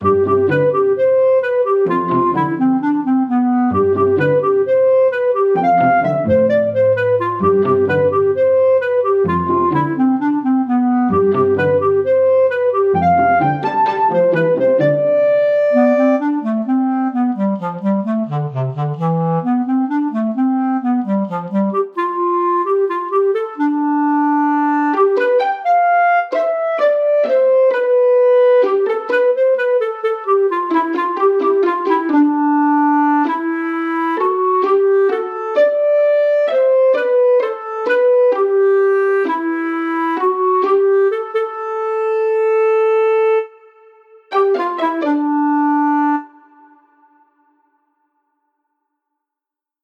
ハスやスイレンが浮かぶ池をイメージした楽曲。何故かPCのスクリーンセーバーも思い浮かぶ。